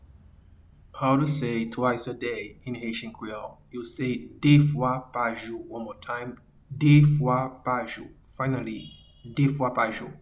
Pronunciation:
Twice-a-day-in-Haitian-Creole-De-fwa-pa-jou.mp3